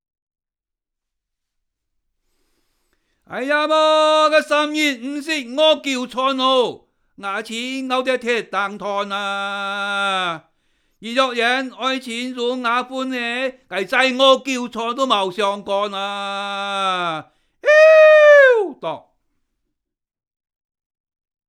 繼3年前〈傳統圍頭．客家歌謠與昔日鄉村生活誌〉的延續，將推出新的客家及圍頭傳統歌謠的光碟。